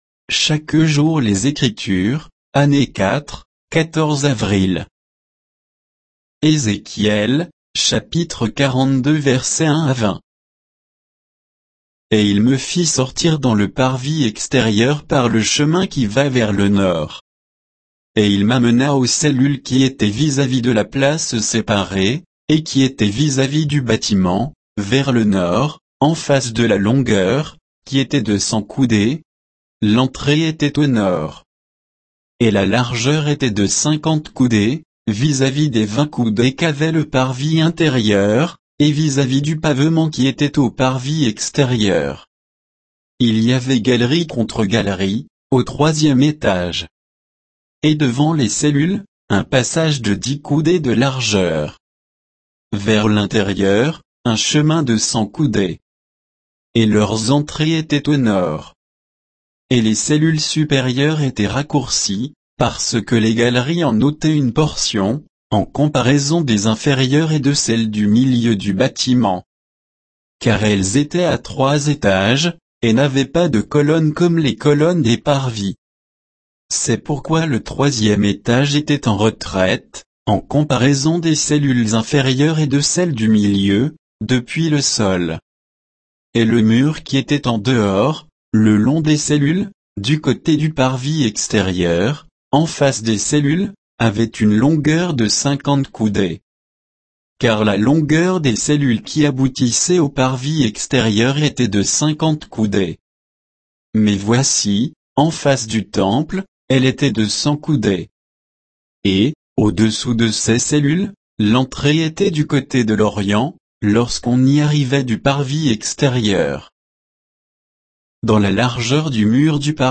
Méditation quoditienne de Chaque jour les Écritures sur Ézéchiel 42